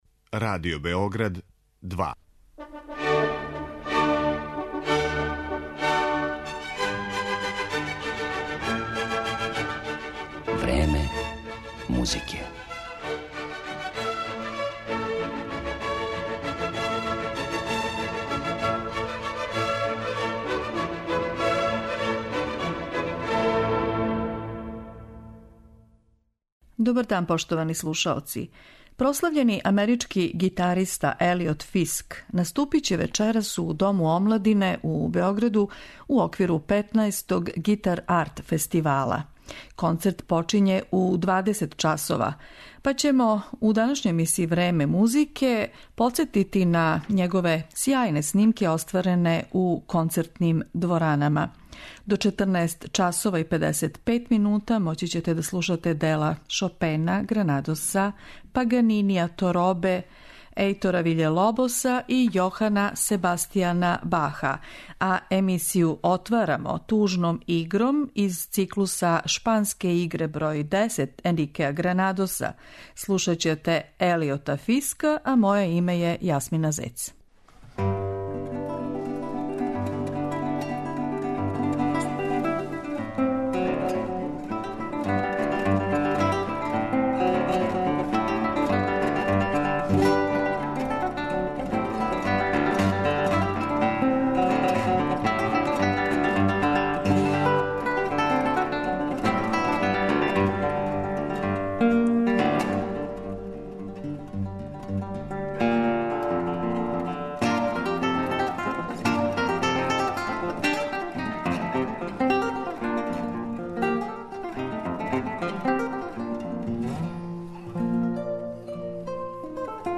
Прослављени амерички гитариста Елиот Фиск одржаће концерт 14. марта у Београду, у Дому омладине, у оквиру 15. Гитар арт фестивала.
Слушаоци ће моћи да чују његове снимке остварене у концертним дворанама пре неколико године. На таласима Радио Београда 2, Елиот Фиск ће извести композиције Паганинија, Гранадоса, Баха, Торобе и Виље Лобоса.